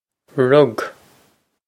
Pronunciation for how to say
Rug
This is an approximate phonetic pronunciation of the phrase.